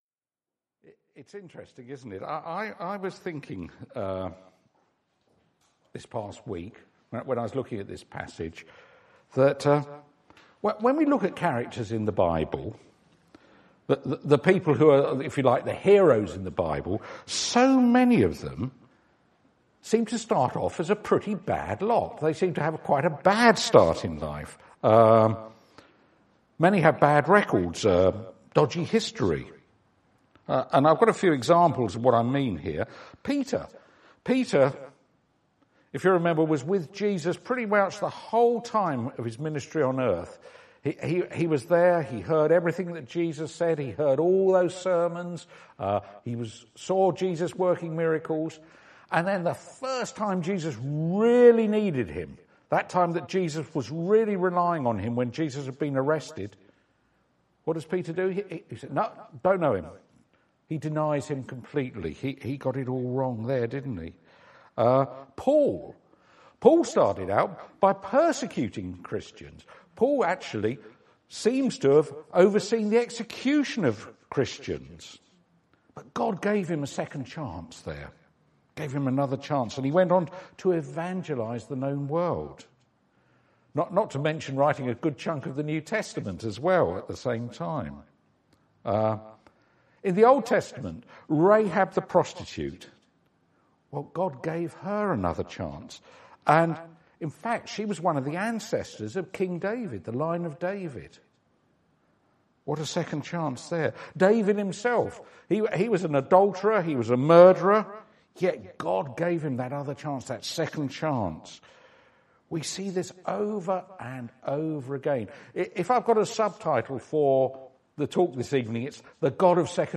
2016 Sep-Dec Audio Sermons — All Saints' Church Laleham